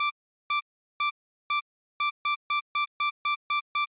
selftimer_4sec.m4a